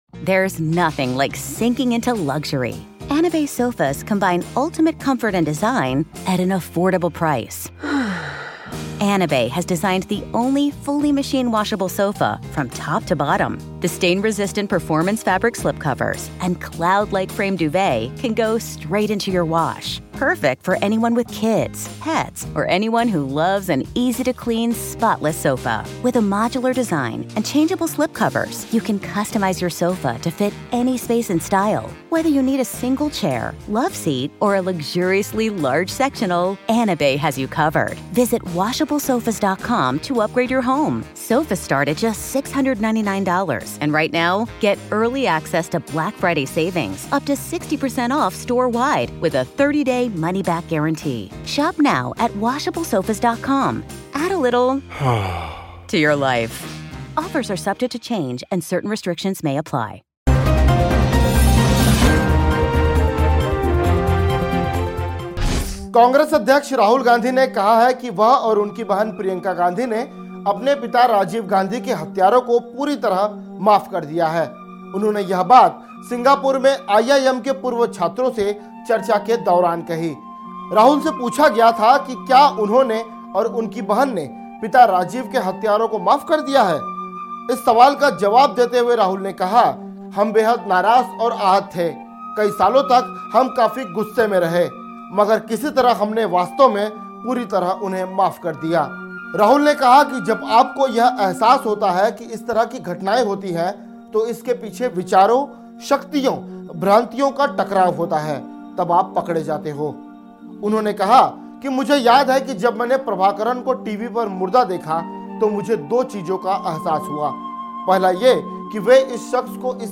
News Report / राहुल गांधी बोले- मैंने और मेरी बहन ने पूरी तरह से माफ कर दिया पिता के हत्‍यारों को